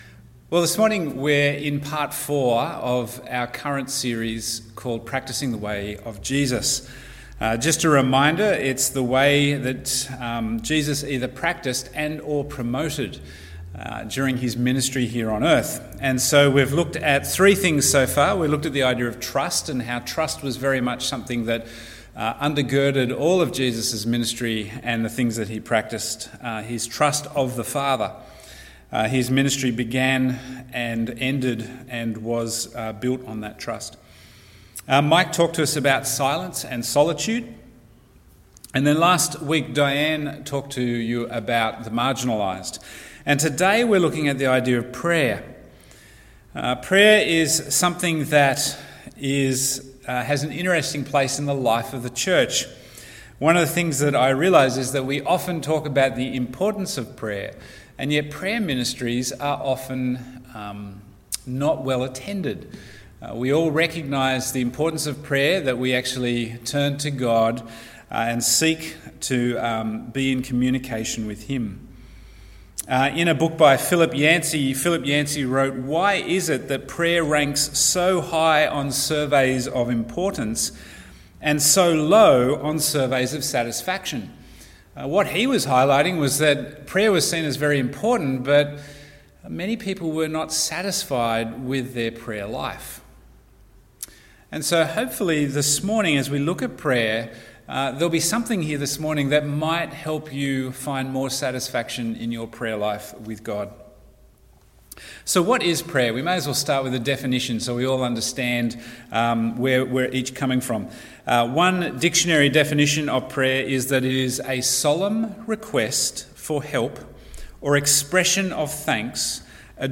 Bible Text: Matthew 6:5-13 | Preacher